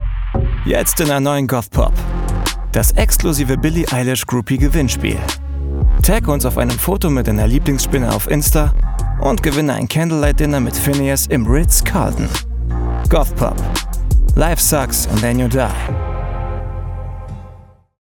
hell, fein, zart, sehr variabel
Jung (18-30)
Werbung Smartphone
Commercial (Werbung)